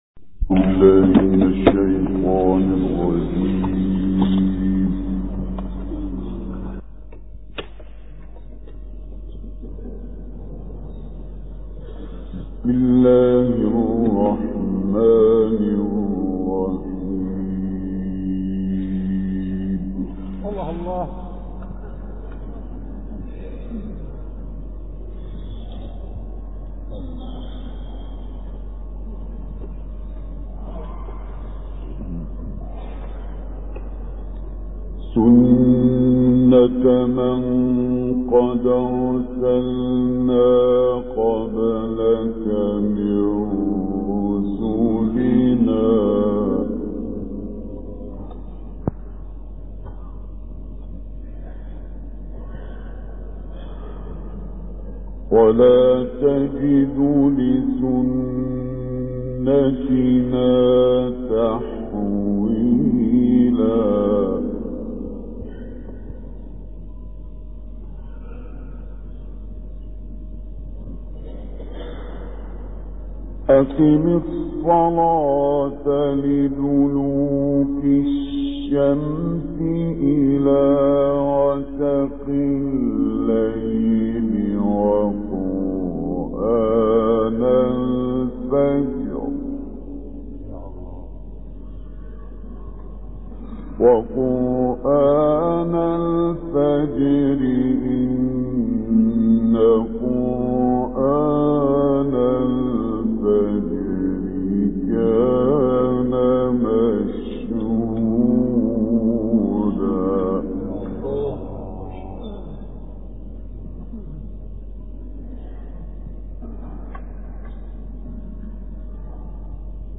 ملف صوتی ما تيسر من سورة الاسراء - 4 بصوت إبراهيم عبدالفتاح الشعشاعي